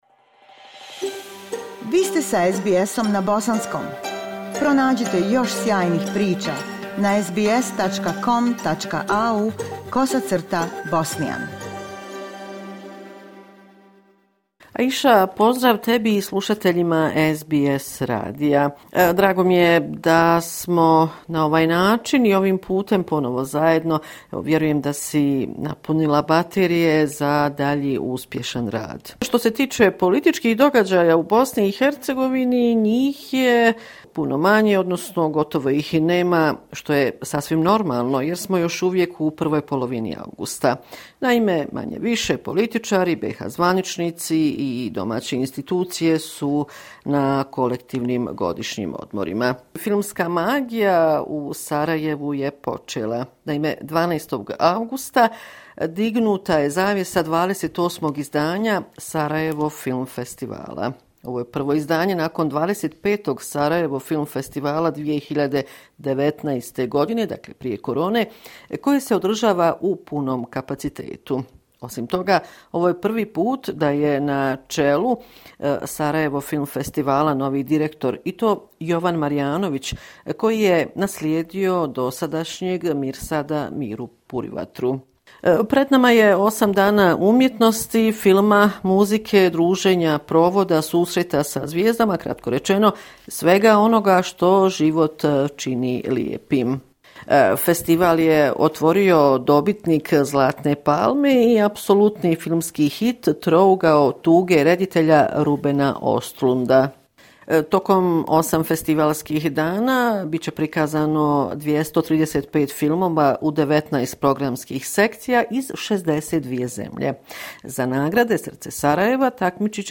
Sedmični izvještaj iz Bosne i Hercegovine, 14.8.22.